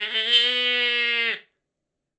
minecraft / sounds / mob / goat / scream3.ogg
scream3.ogg